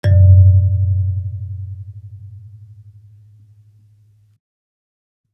kalimba_bass-F#1-ff.wav